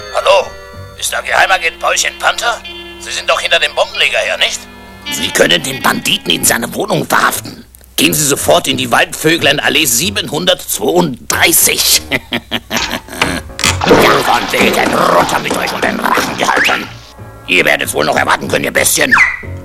- Geheimagent
pp-geheimagent.mp3